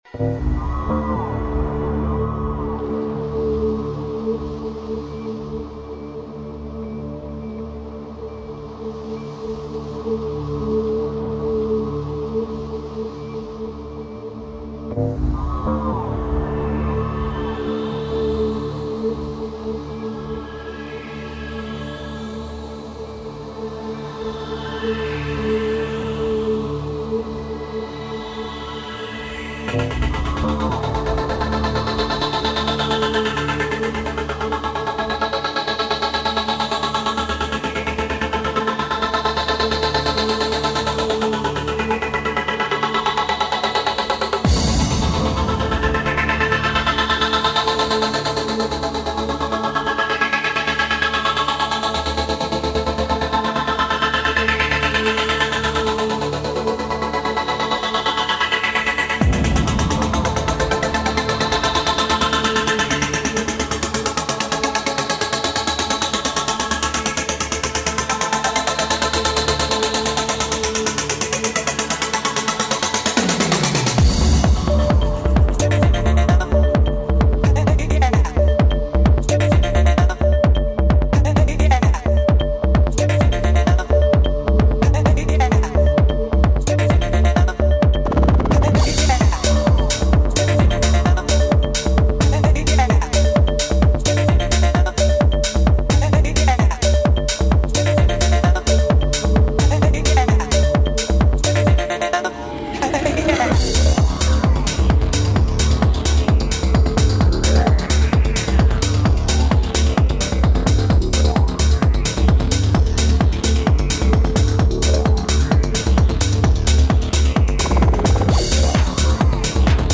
Транс музыка